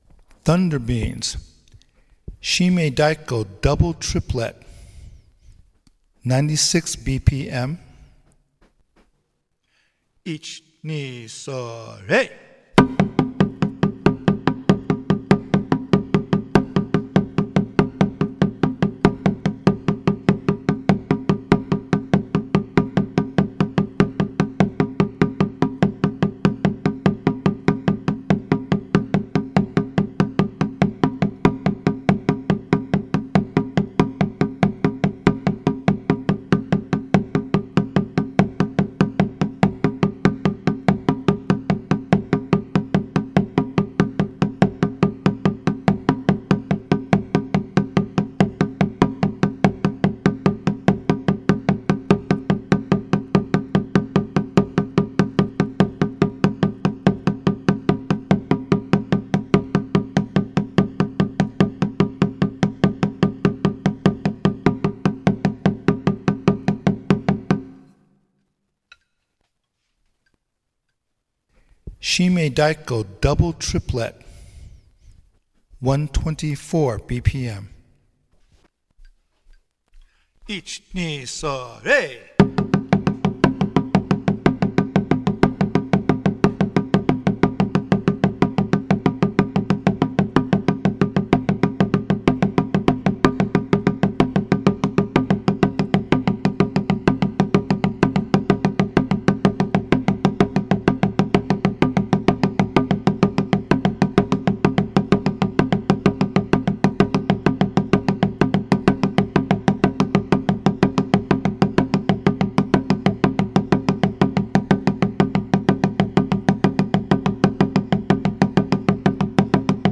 A recording of the “double-triplet”shimedaiko patterns of Kaminari Tamashi at 96 and 124 BPM.
ThunderBeings_Shimedaiko.mp3